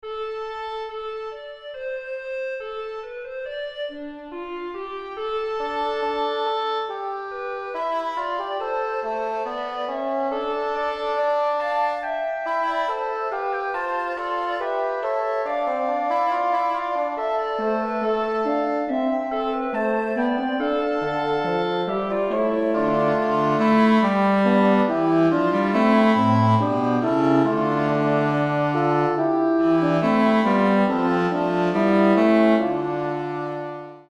Zink, Flöte, Posaune,Gambe,Dulcian